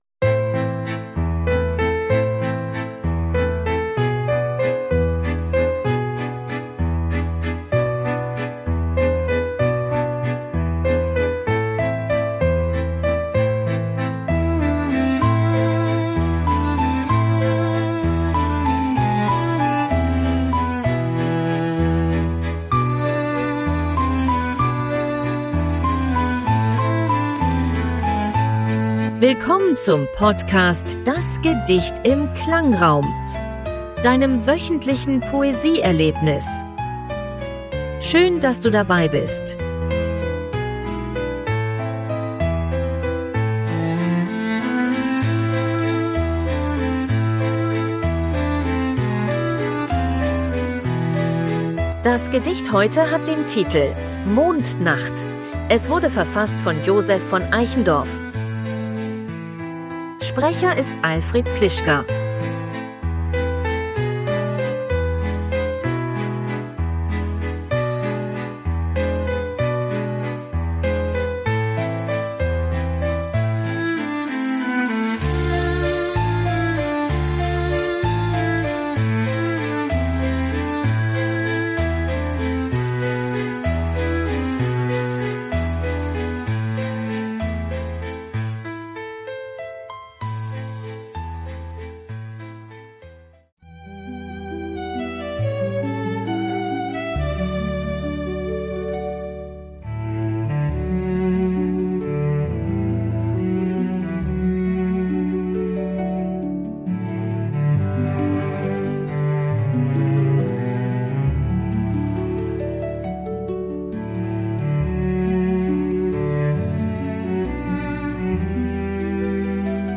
stimmungsvollen Klangkulisse.
begleitet von KI-generierter Musik.